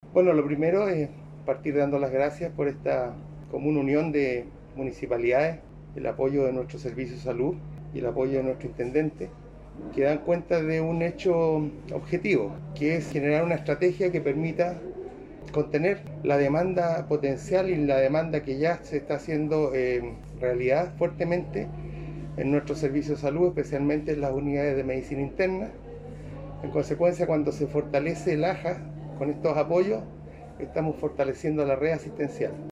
El director (s) del Servicio de Salud Biobío, Juan Carlos González, explicó que entre estos recintos extrahospitalarios se encuentra el Hospital de Campaña de Laja, que dependerá técnicamente del Hospital de la Familia y la Comunidad.
05-director-servicio-salud-biobio.mp3